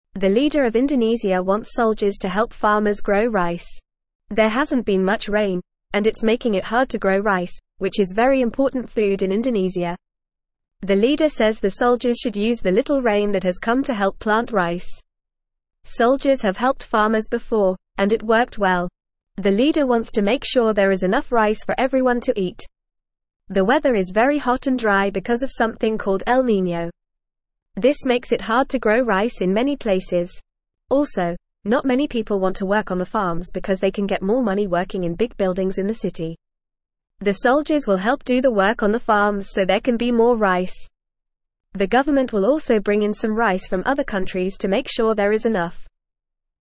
Fast
English-Newsroom-Beginner-FAST-Reading-Soldiers-to-Help-Farmers-Grow-Rice-in-Indonesia.mp3